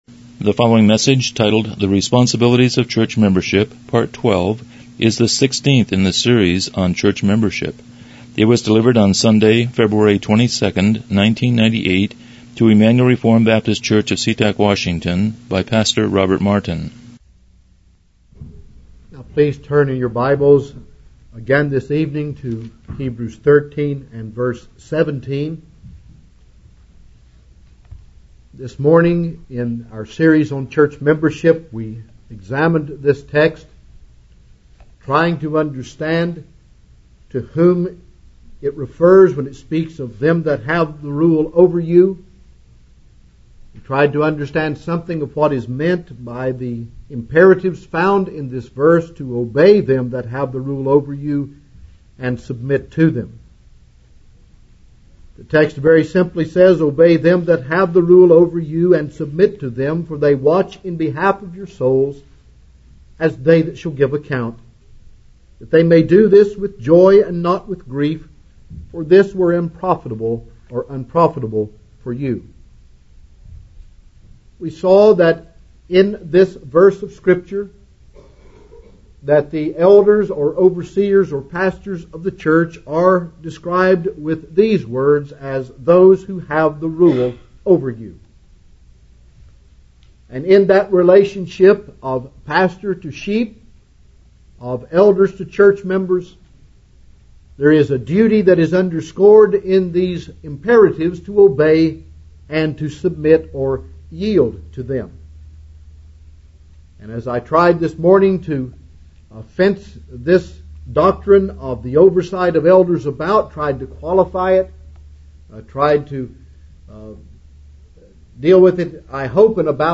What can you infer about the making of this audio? Church Membership Service Type: Evening Worship « 15 Responsibilities of